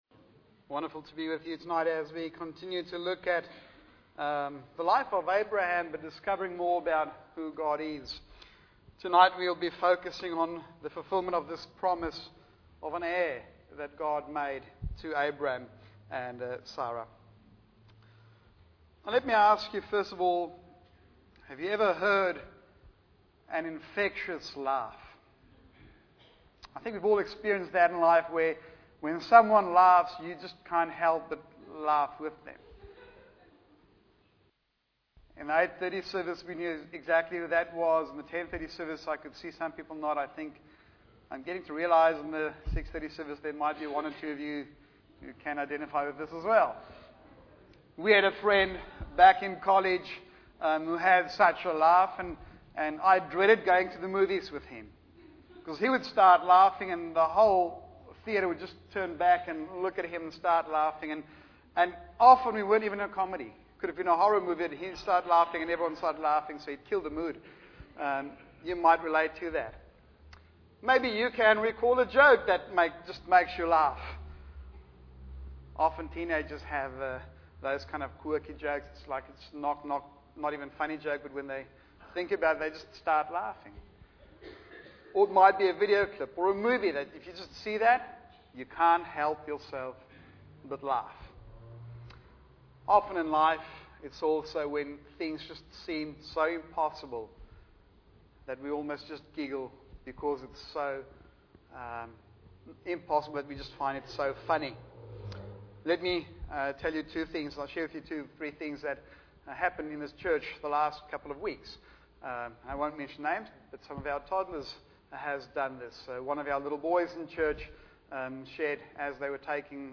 Bible Text: Genesis 15:1-5 | Preacher